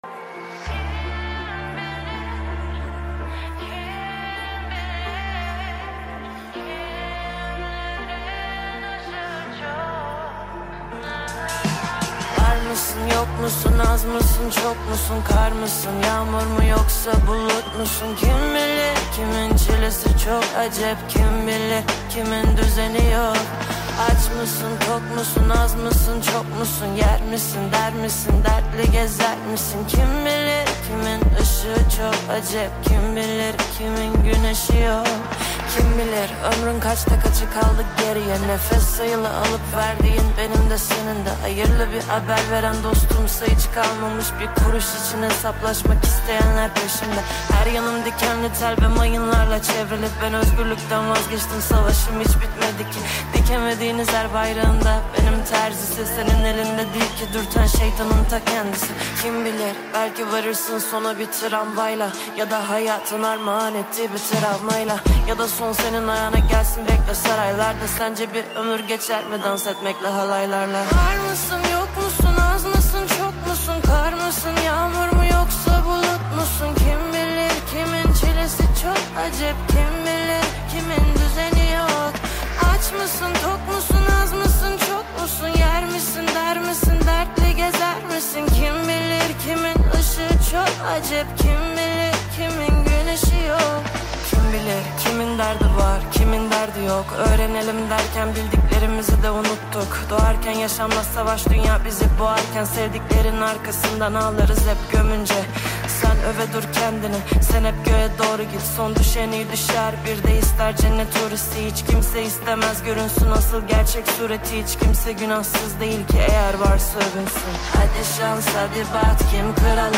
• Жанр: Новые каверы